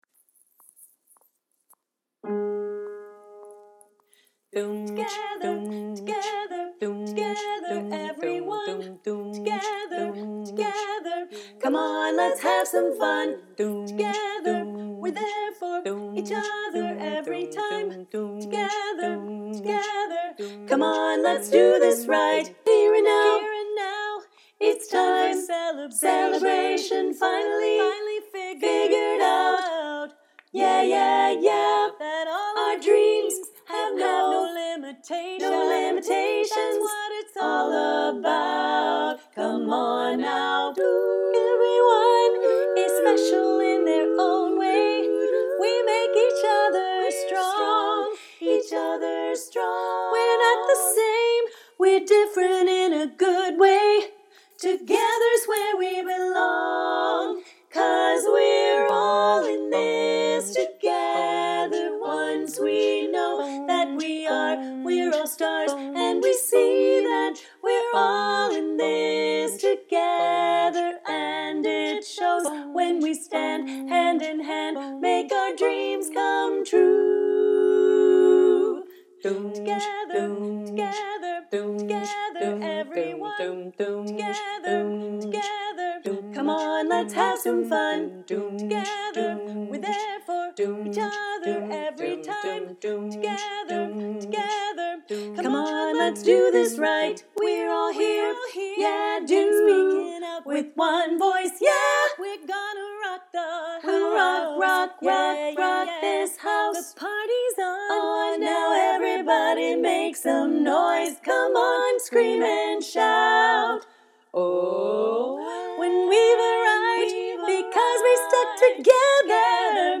Bari